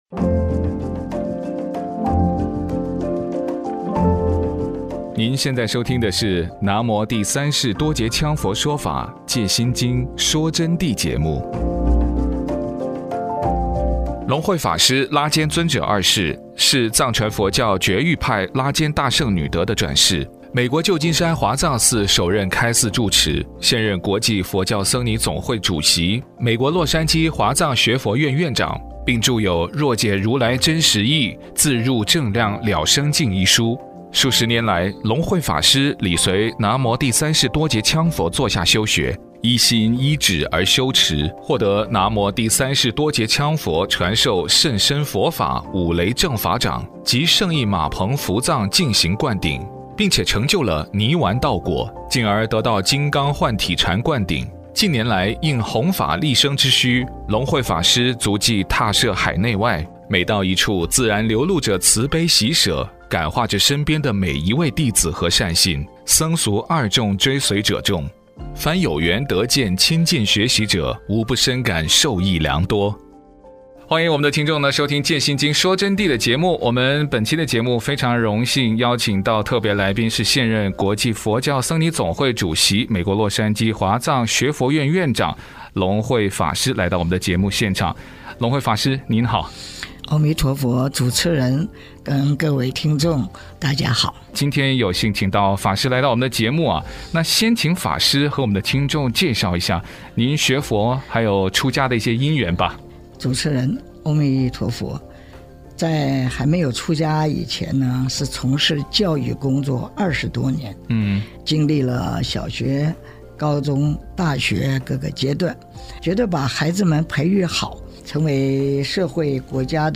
佛弟子访谈（三十一）佛教分宗派的缘由？为什么说佛教是盲目崇拜偶像的说法是错的？烧香拜佛求保佑有用吗？